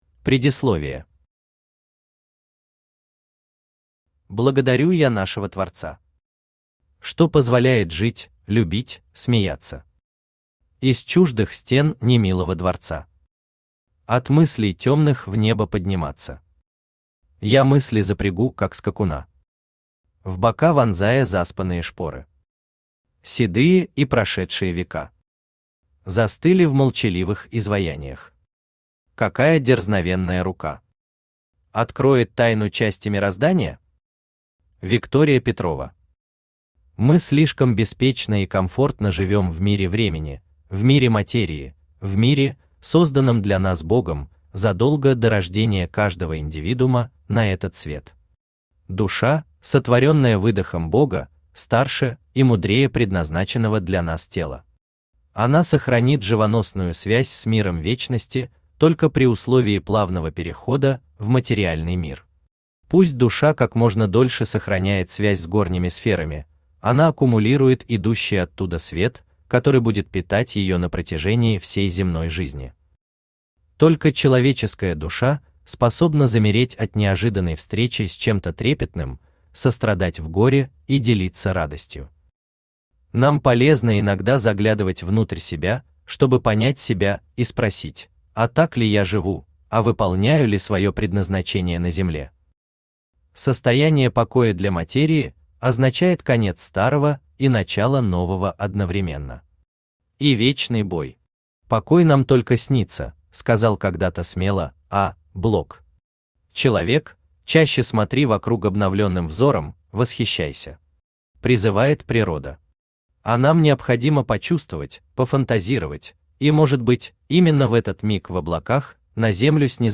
Аудиокнига Тайны мироздания | Библиотека аудиокниг
Aудиокнига Тайны мироздания Автор Виктор Евгеньевич Бабушкин Читает аудиокнигу Авточтец ЛитРес.